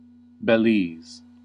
^ /bɪˈlz, bɛ-/
bih-LEEZ, beh-; Belize Kriol English: Bileez
En-us-Belize.ogg.mp3